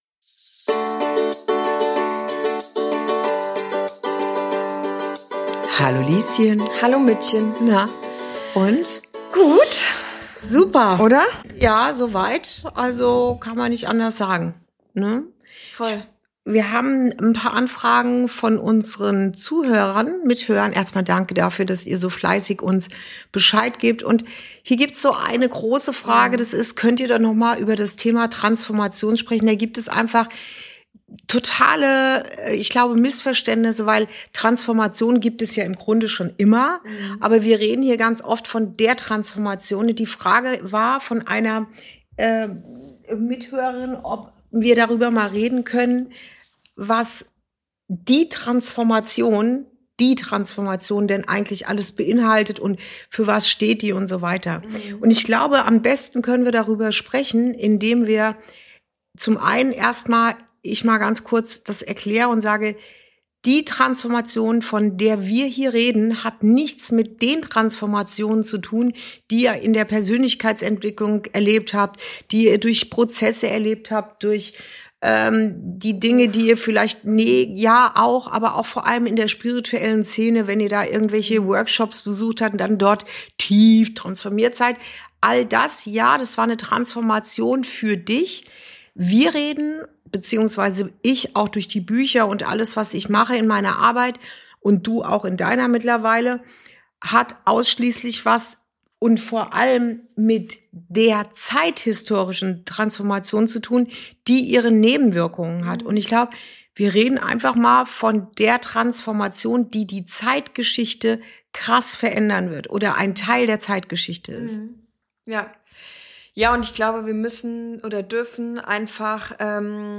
Folge 35: Wir gehen in eine neue Zeit – über die zeithistorische Transformation ~ Inside Out - Ein Gespräch zwischen Mutter und Tochter Podcast